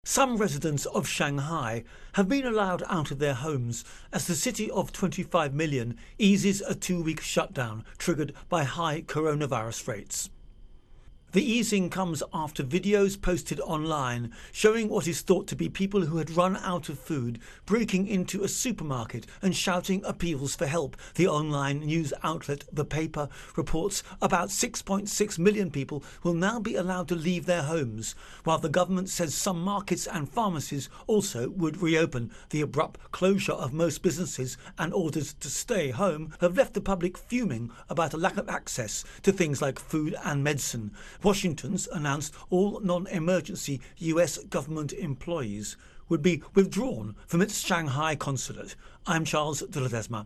Virus Outbreak China Intro and Voicer